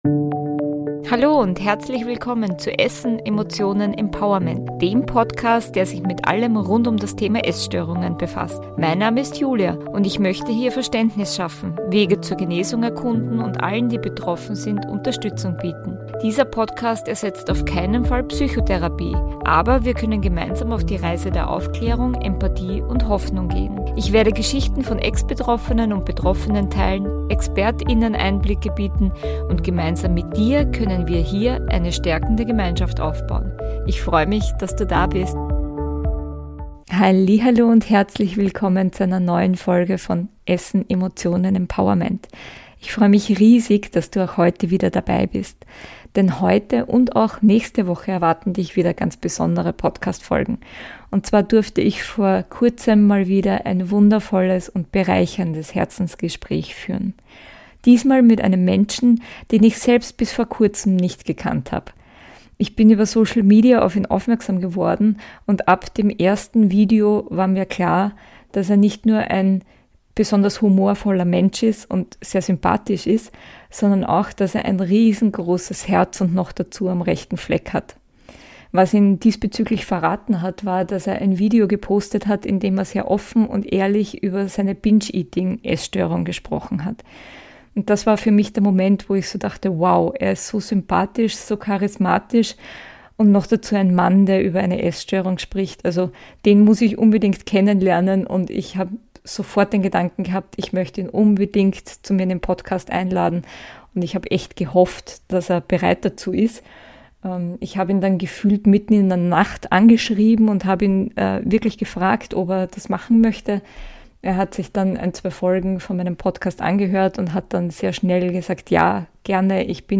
Beschreibung vor 2 Jahren In der Folgenreihe "Herzensgespräch" lade ich Menschen zum Gespräch ein, die aufgrund ihrer Expertise, ihrer persönlichen Erfahrungen und/oder ihrer Empathie einen wichtigen Beitrag zum Thema Essstörungen leisten wollen. Setz dich mit uns zusammen an einen Tisch und lass nicht nur inspirieren, sondern auch berühren.